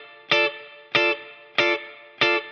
DD_TeleChop_95-Dmin.wav